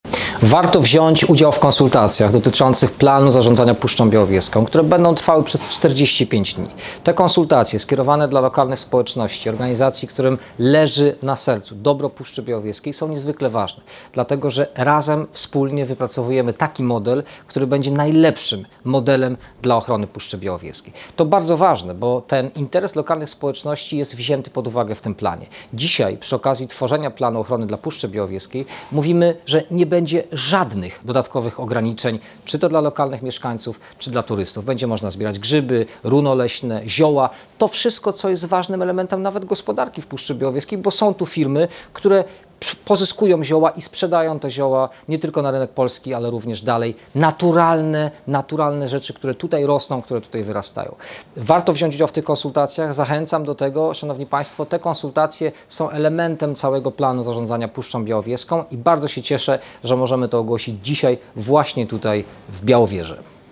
pobierz wypowiedź wiceministra Mikołaja Dorożały: konsultacje Planu zarządzania Obiektem Światowego Dziedzictwa Białowieża Forest (część polska)